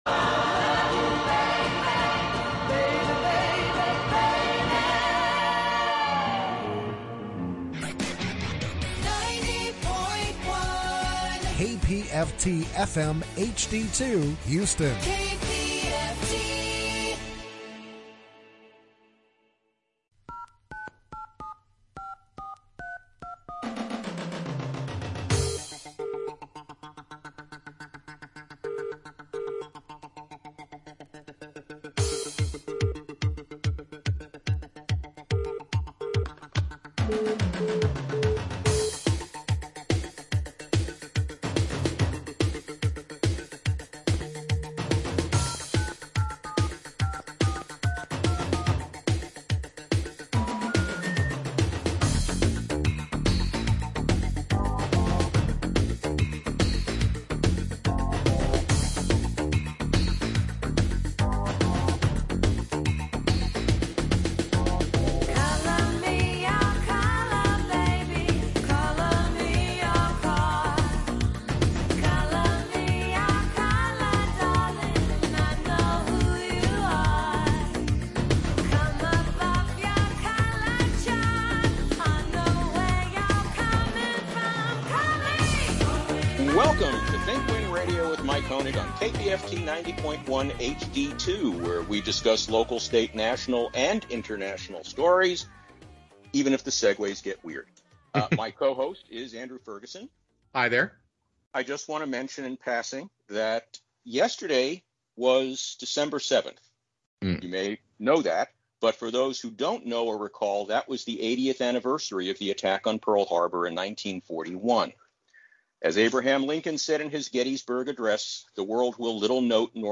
The recording attached is the original edition intended for that broadcast.